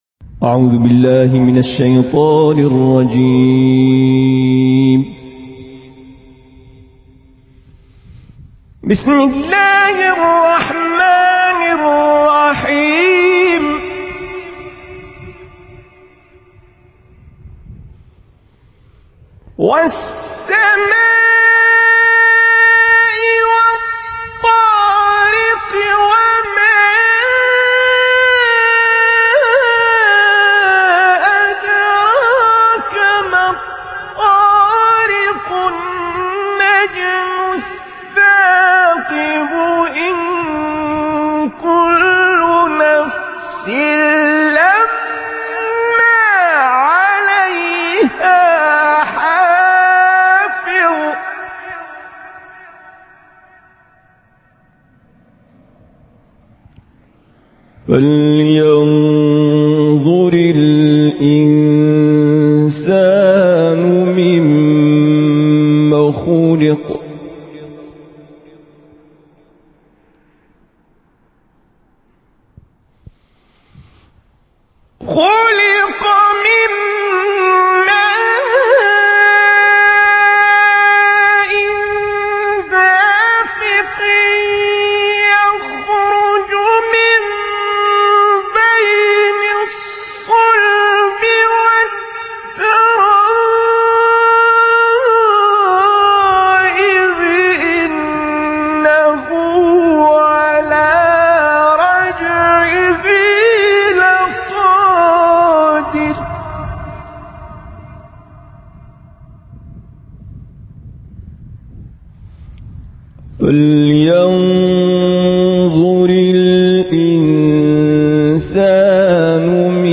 تلاوت سوره الطارق